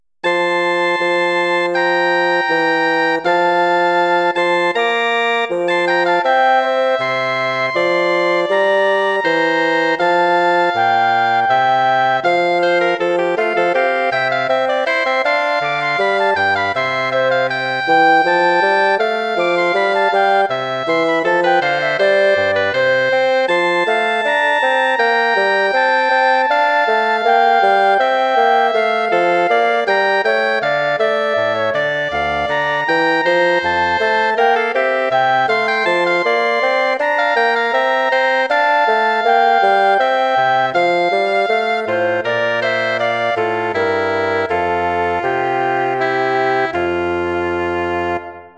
5 Stücke Bearbeitung für Holzbläsertrio 01
Besetzung: Flöte, Oboe, Fagott
Arrangement for woodwind trio
Instrumentation: flute, oboe, bassoon